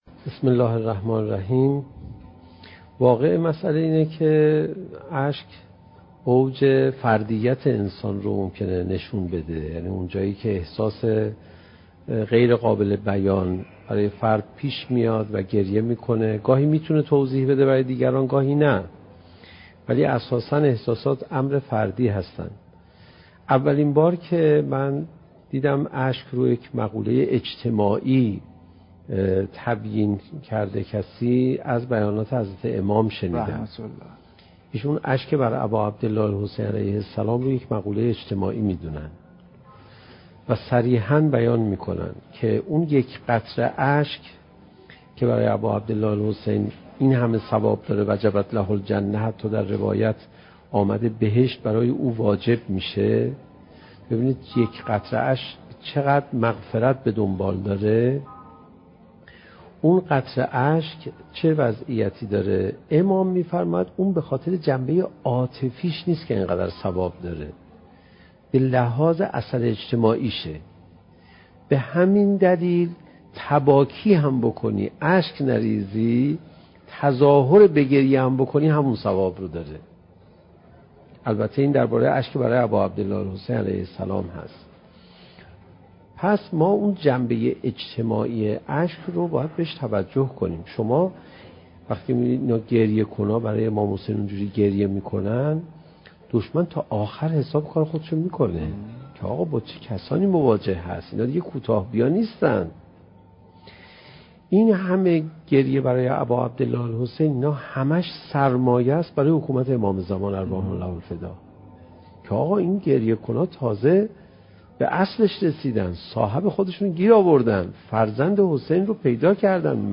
سخنرانی حجت الاسلام علیرضا پناهیان با موضوع "چگونه بهتر قرآن بخوانیم؟"؛ جلسه ششم: "اشک، قله ایمان"